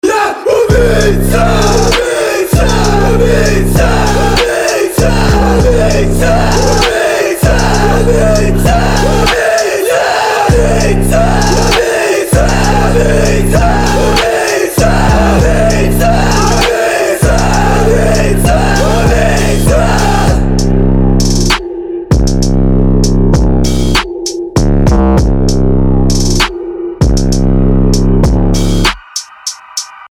• Качество: 320, Stereo
жесткие
мощные басы
Cloud Rap
устрашающие
Кусок хоррор-трека в стиле cloud rap.